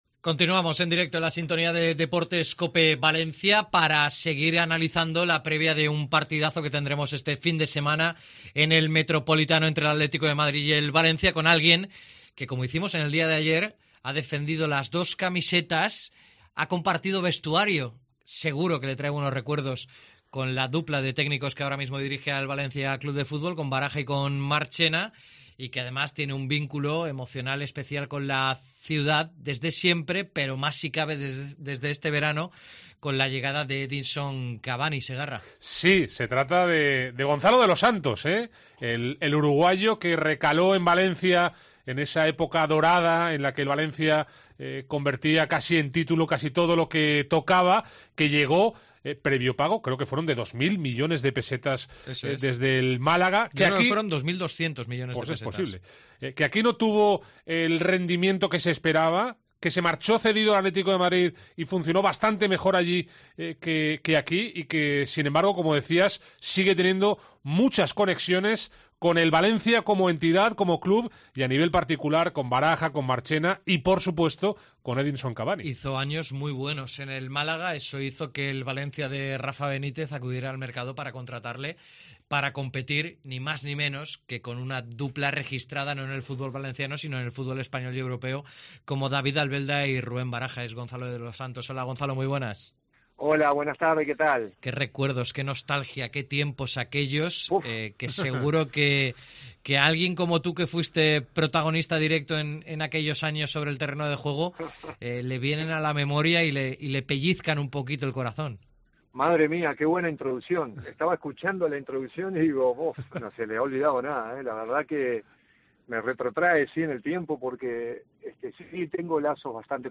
AUDIO. Entrevista con Gonzalo de los Santos